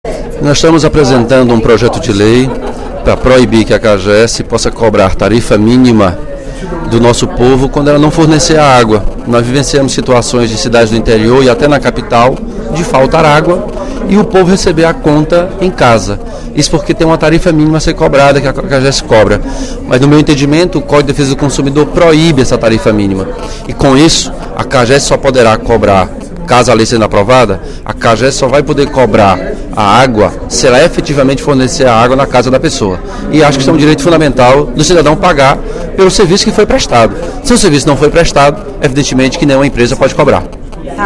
O deputado Elmano de Freitas (PT) destacou, durante o primeiro expediente da sessão plenária desta terça-feira (07/04), projeto de lei de sua autoria que proíbe a cobrança de tarifa e taxa mínima por empresas que prestam abastecimento de água no Ceará. Segundo o parlamentar, a matéria busca vedar a possibilidade de abuso que a população pode sofrer de empresas que cobram contas de água, mas não oferecem o abastecimento adequadamente.